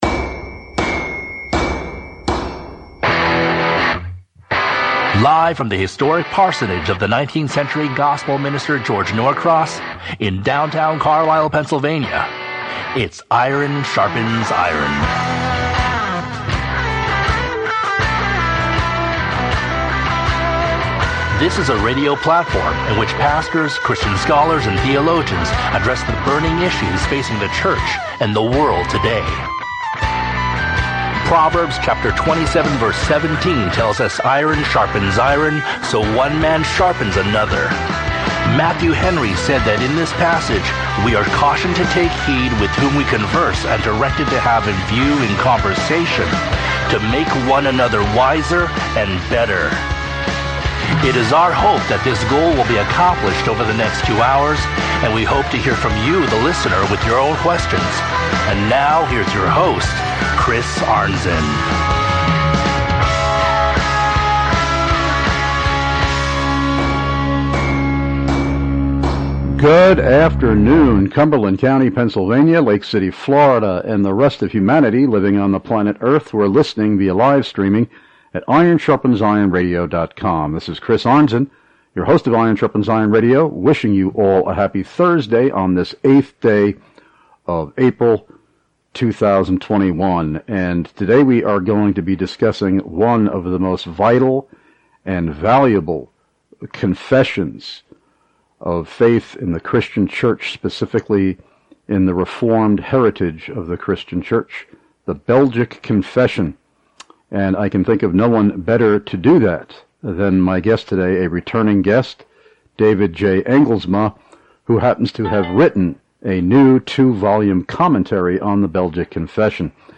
Lectures/Debates/Interviews